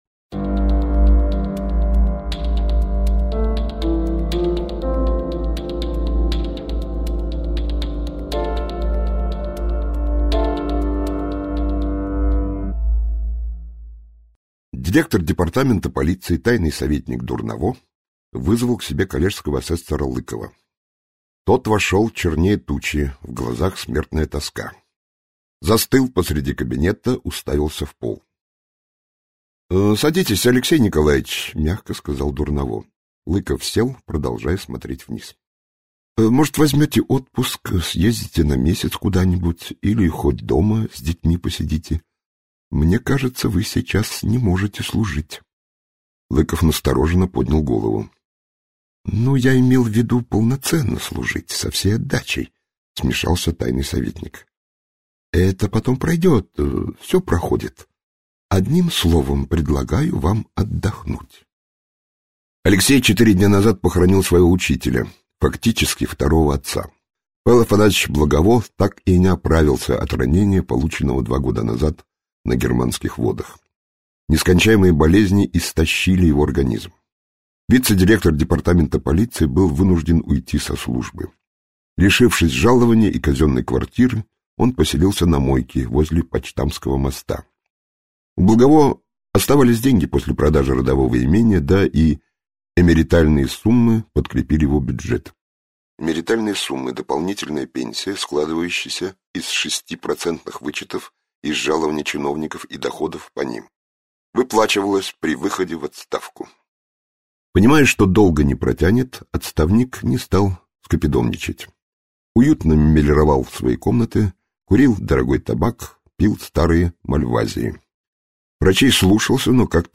Аудиокнига Мертвый остров - купить, скачать и слушать онлайн | КнигоПоиск